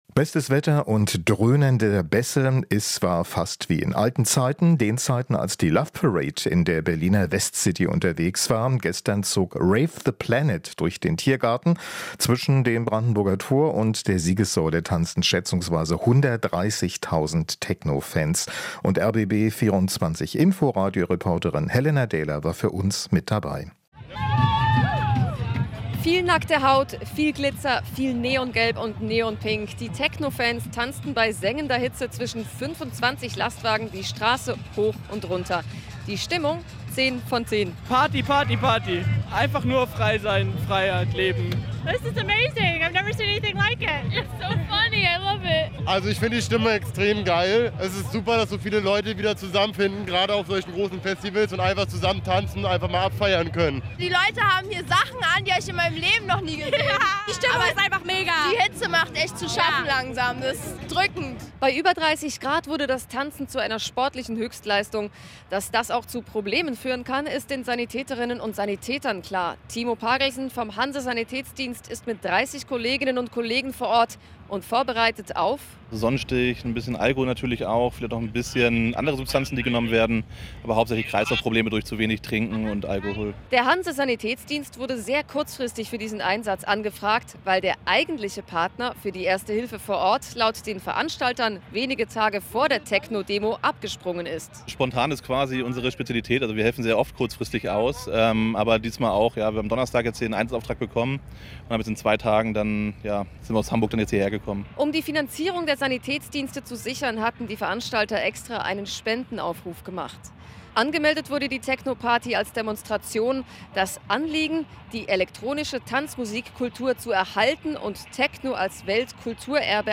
Rund 200.000 Menschen kommen zum Rave the Planet. Die Hitze machte einigen zu schaffen.